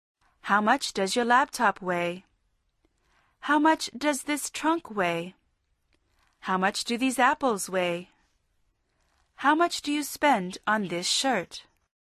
Repítelos luego en voz alta tratando de imitar la entonación.